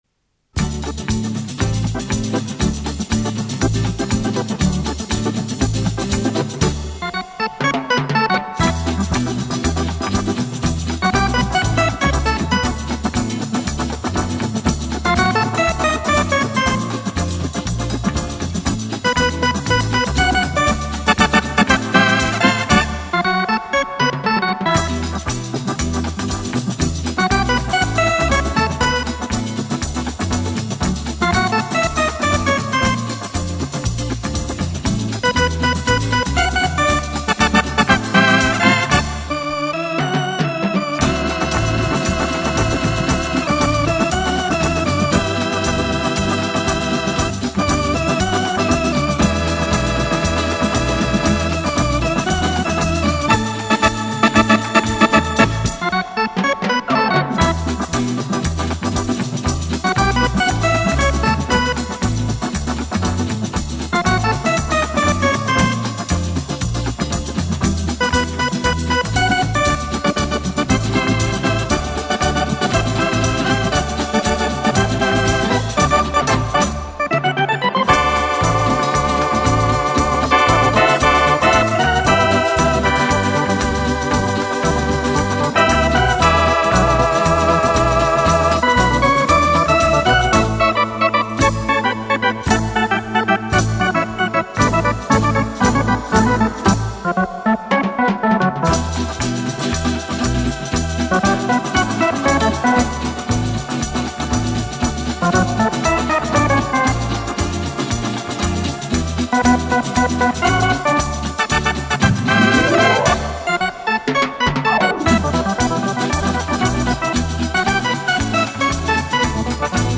收集来就是低品质的MP3，不过听起来没什么明显差别